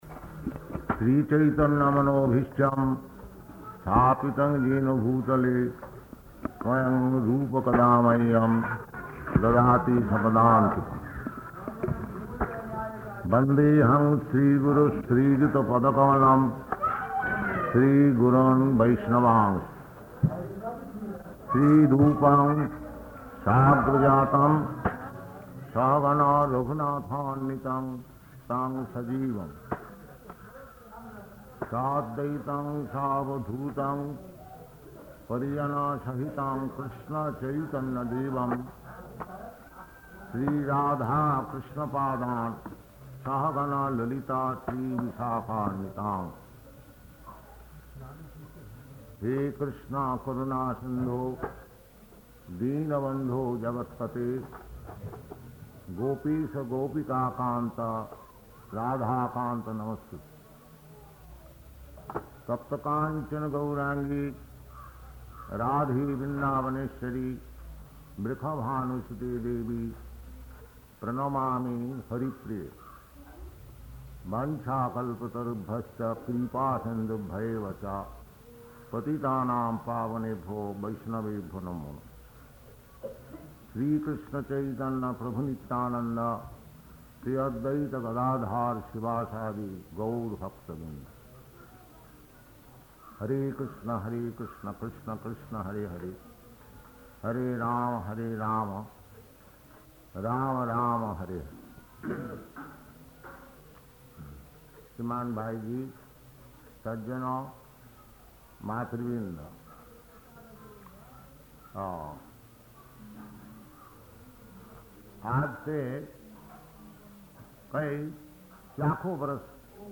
Lecture in Hindi
Type: Lectures and Addresses
Location: Gorakphur